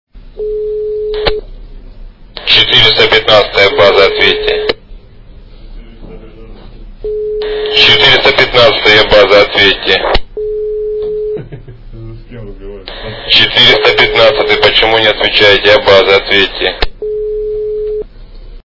» Звуки » Смішні » Голос по рации - 415 база ответьте!
При прослушивании Голос по рации - 415 база ответьте! качество понижено и присутствуют гудки.
Звук Голос по рации - 415 база ответьте!